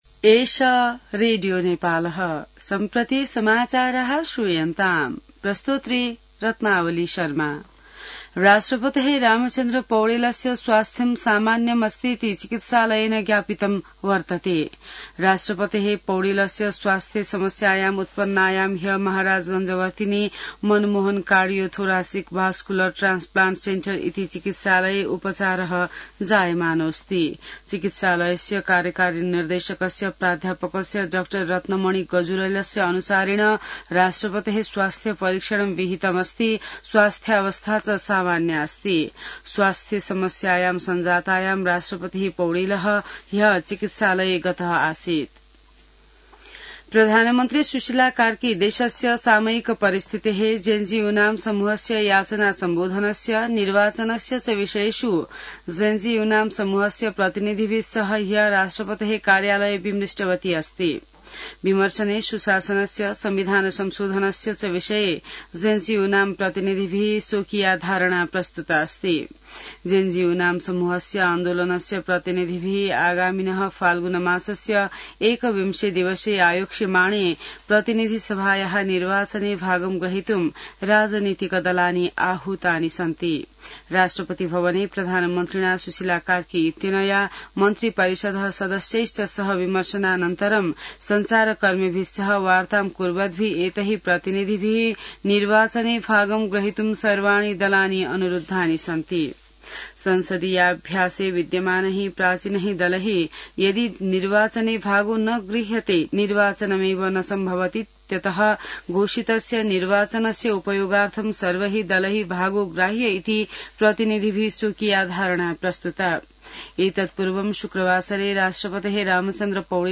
संस्कृत समाचार : २६ असोज , २०८२